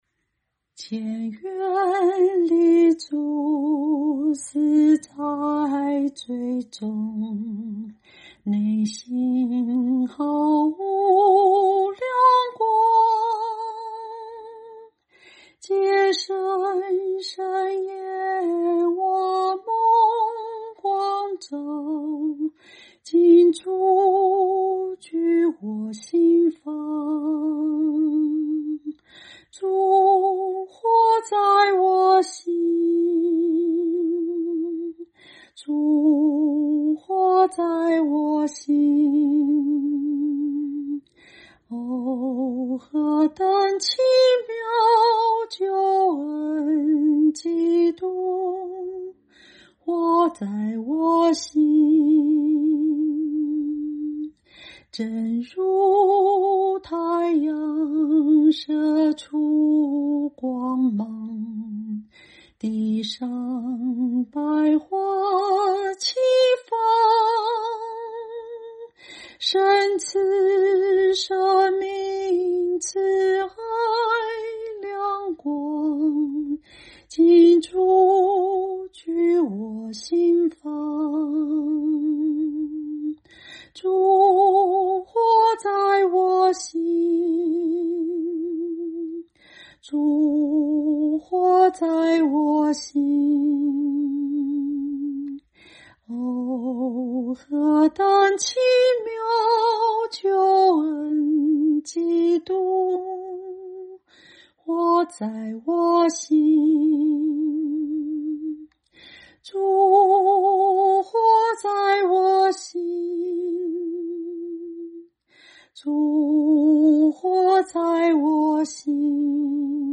清唱    伴奏